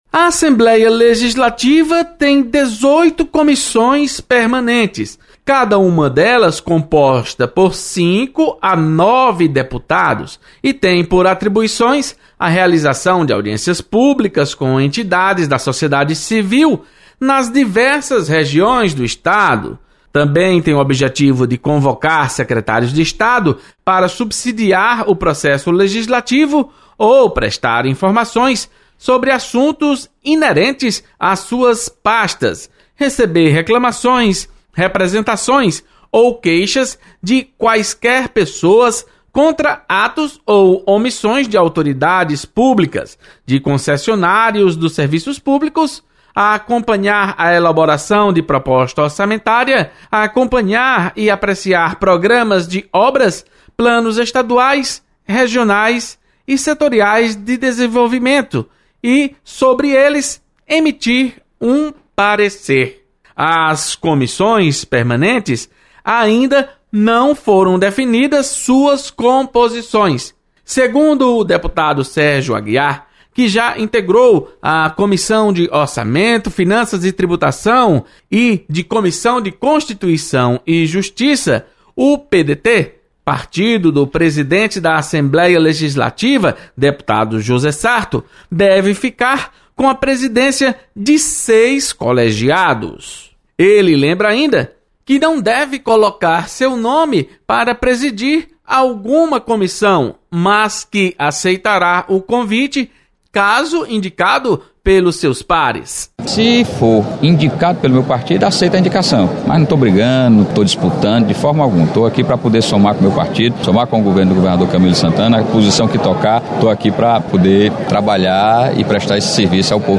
Deputado Sérgio Aguiar e Salmito Filho falam sobre composição das comissões técnicas.